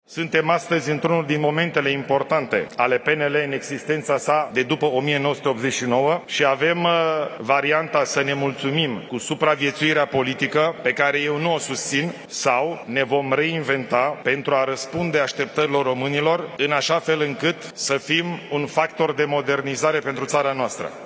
*Ilie Bolojan, discurs în cadrul Congresului Extraordinar al Partidului Național Liberal – 12 iulie 2025*